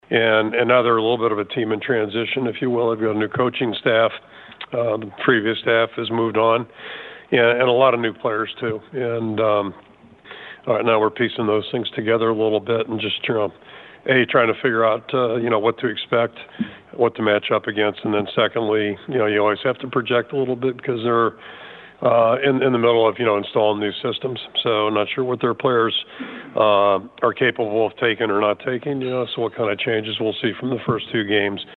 That’s Iowa coach Kirk Ferentz who says Troy is used to success after posting 11 wins last season.